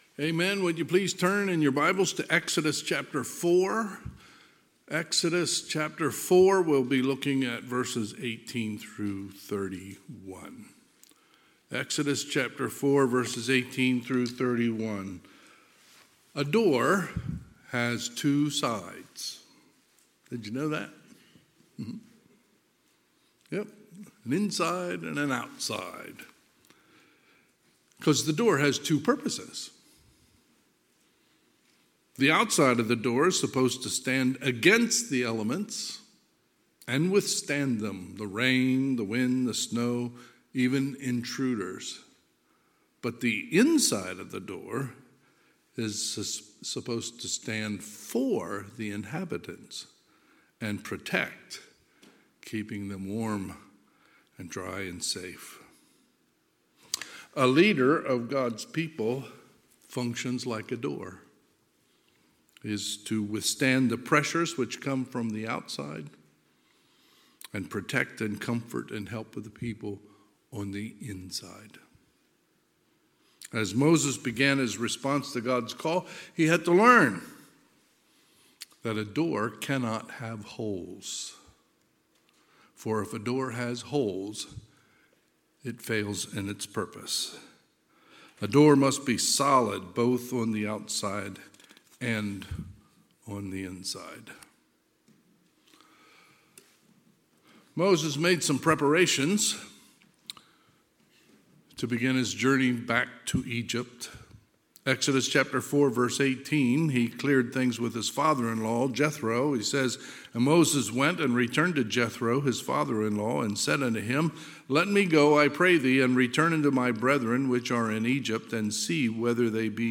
Sunday, June 18, 2023 – Sunday PM